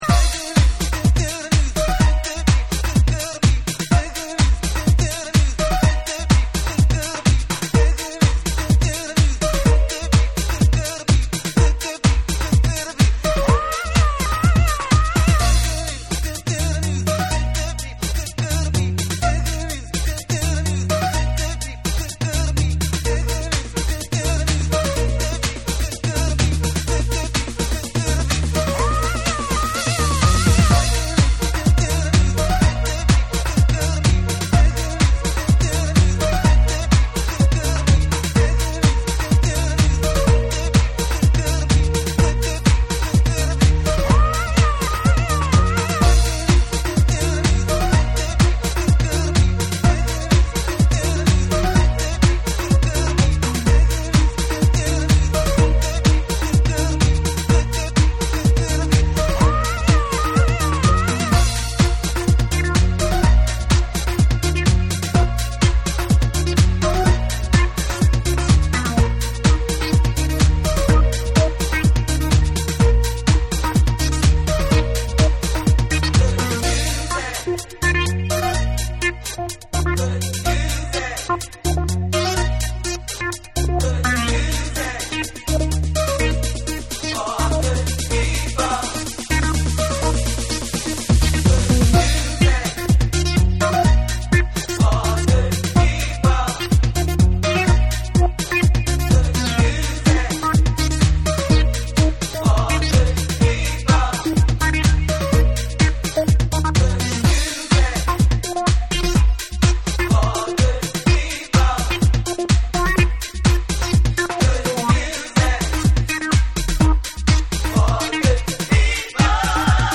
日本のハウス・ミュージックDJ/音楽 プロデューサー
TECHNO & HOUSE / JAPANESE